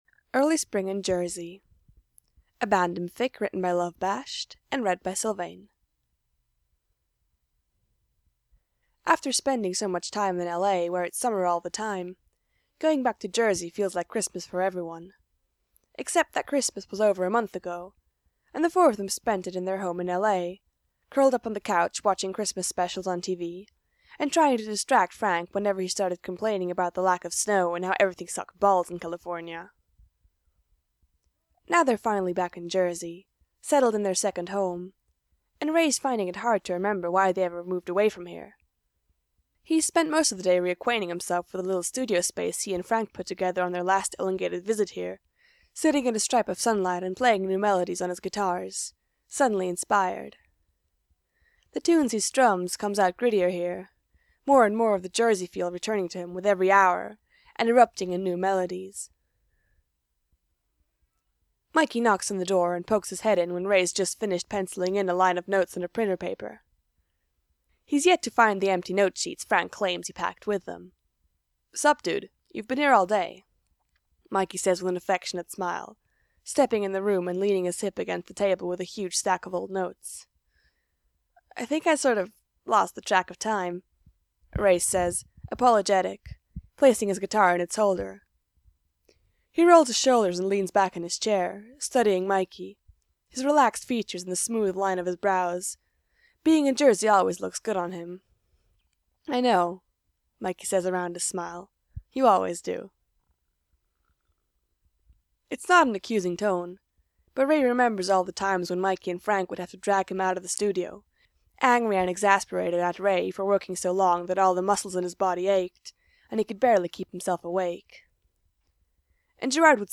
End-of-the-year last-minute podfics